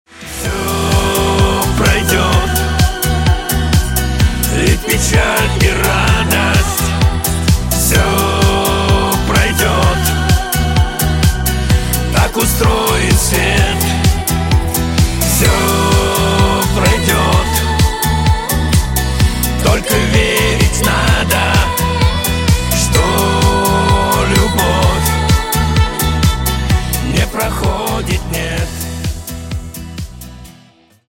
# Шансон Рингтоны